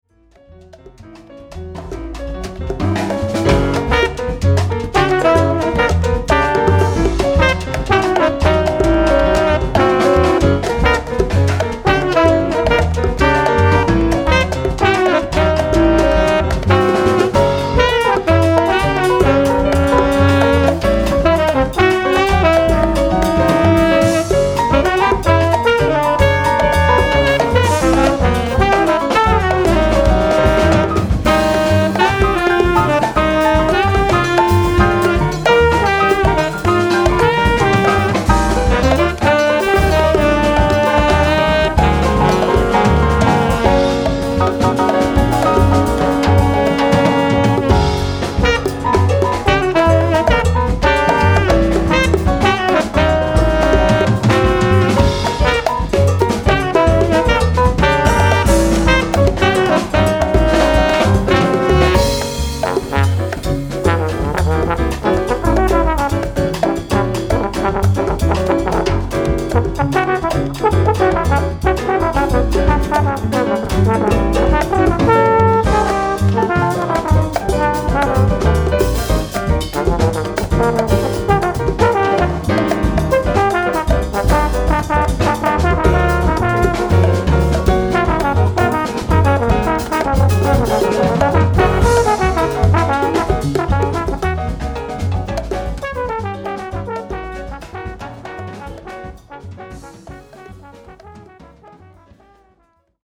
cajon